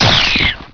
monsters / rat / death1.wav
death1.wav